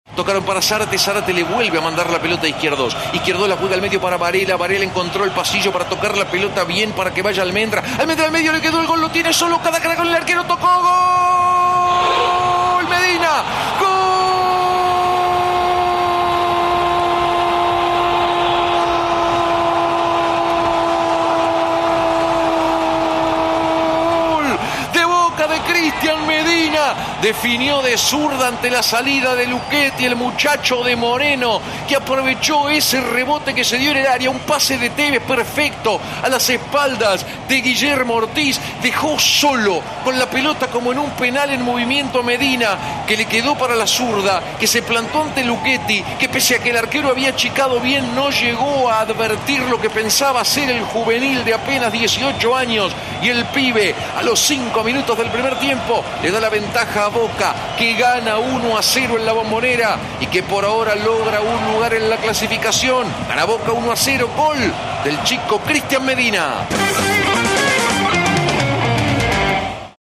Escucha los goles en el Relato